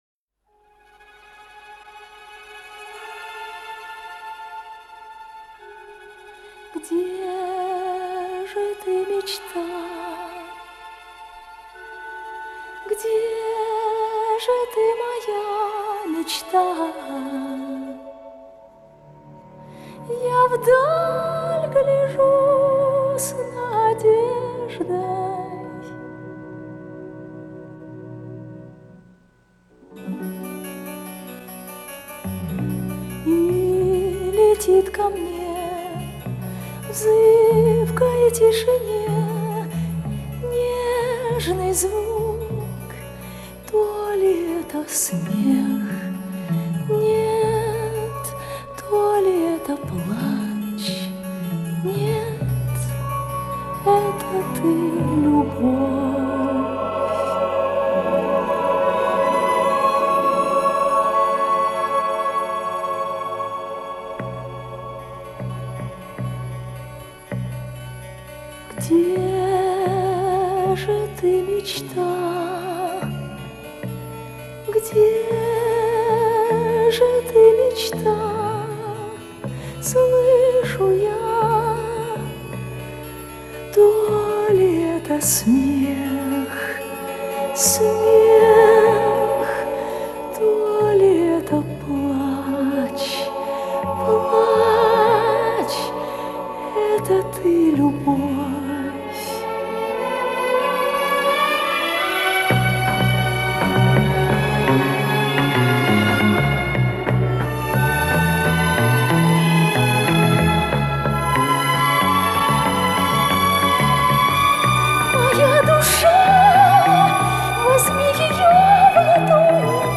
Высокие ноты чистые....